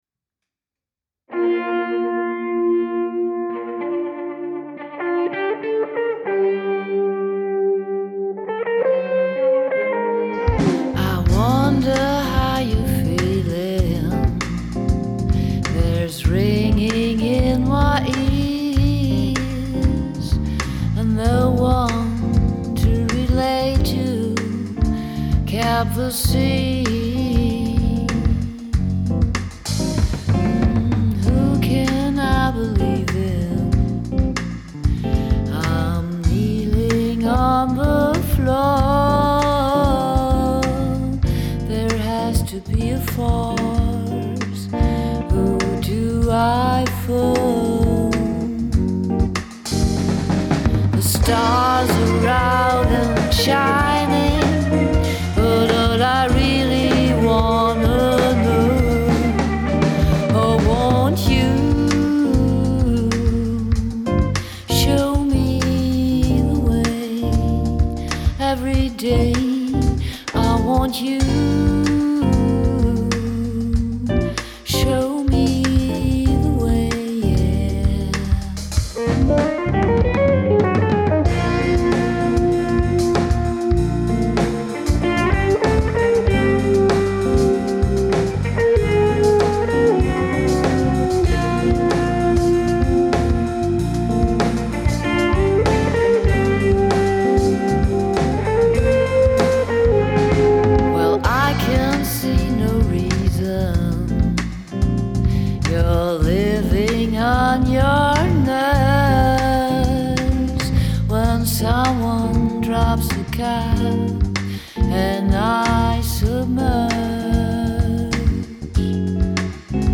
Unsere Coverversion
Gesang/Drums
Gitarre
Saxofon